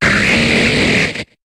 Cri de Libégon dans Pokémon HOME.